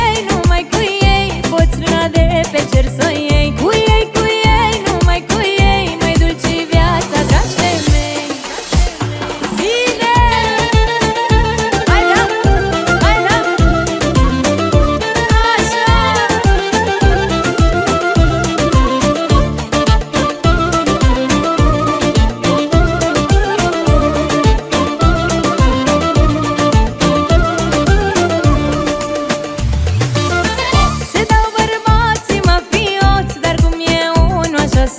Жанр: Кантри
# Contemporary Country